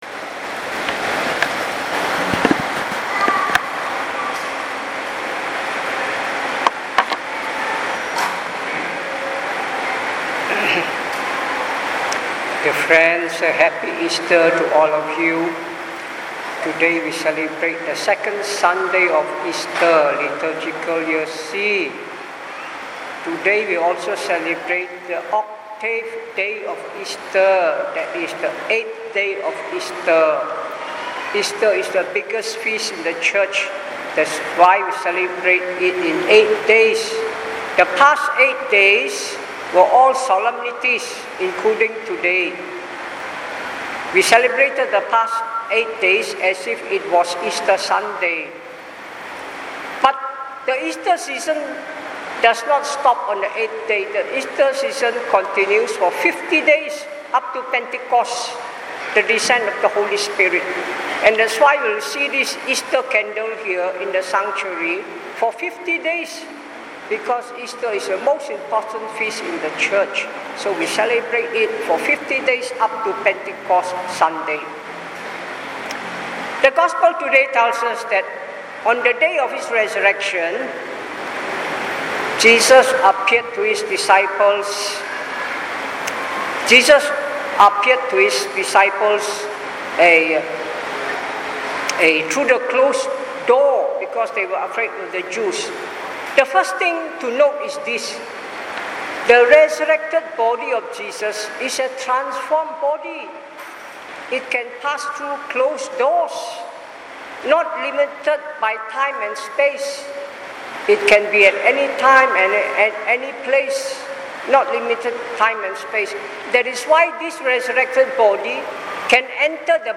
2nd Sunday of Easter (Year C) – Divine Mercy Sunday – 28th April 2019 – English Audio Homily